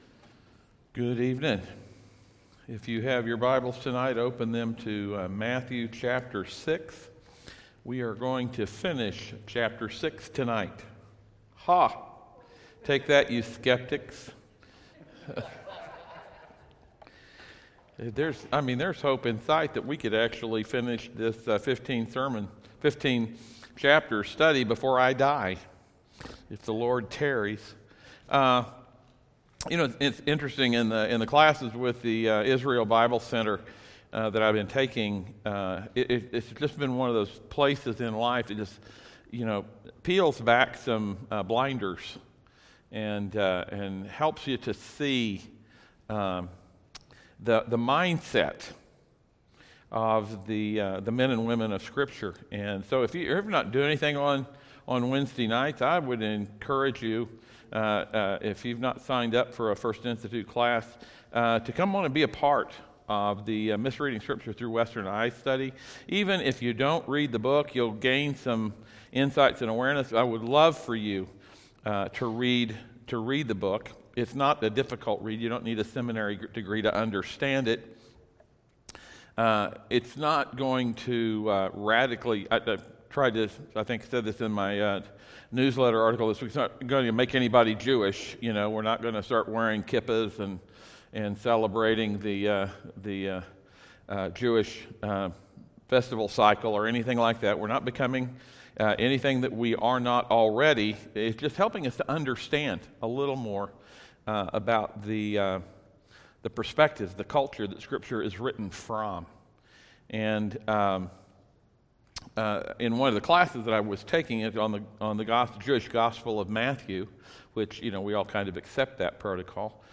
Matthew 6:19-34 Service Type: audio sermons « Bad Ideas